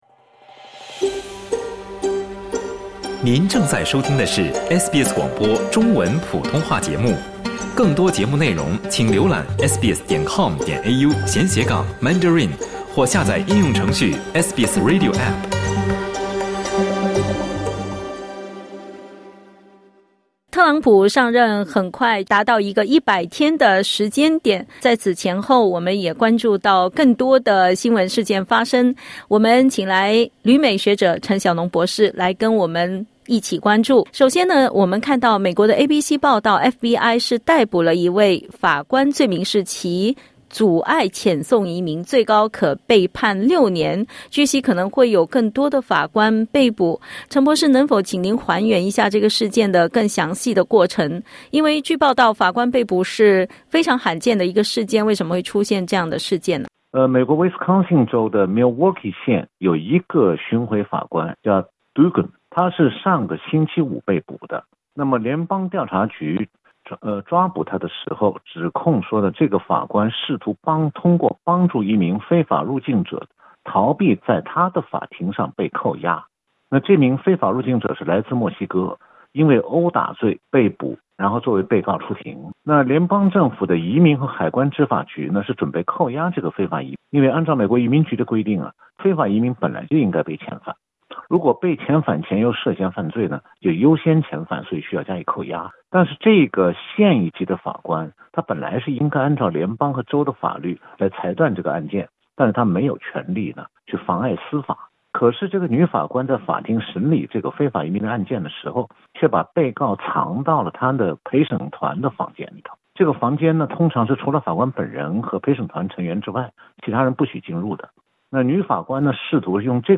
点击音频收听详细内容 （采访内容仅为嘉宾观点。）